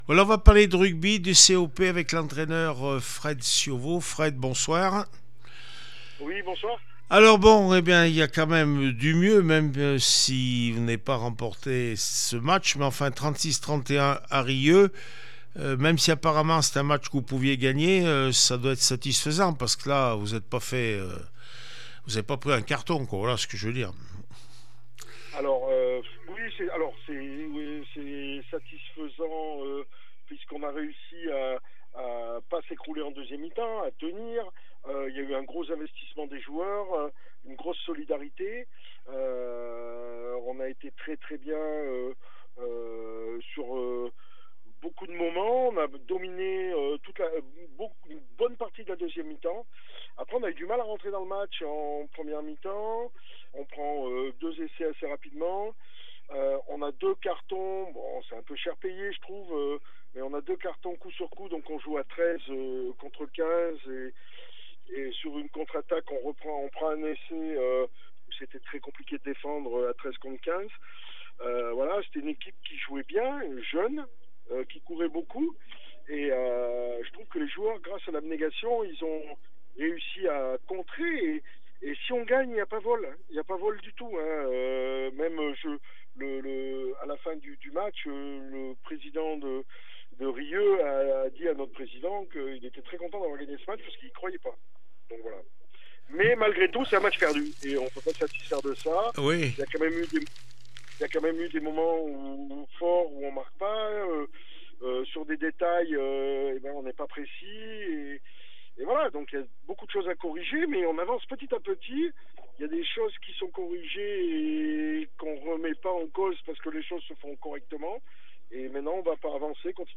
17 février 2025   1 - Sport, 1 - Vos interviews